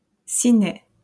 Ciney (French pronunciation: [sinɛ]